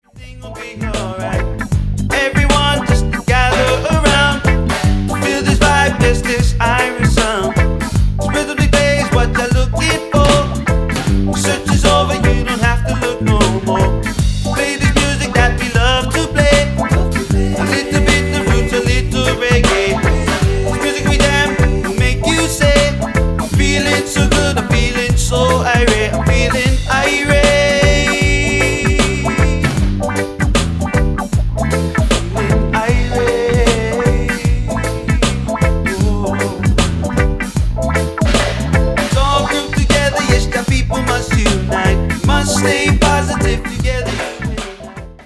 • Genre: Urban, hip-hop, soul.
the rush of reggae, the hustle of hip-hop.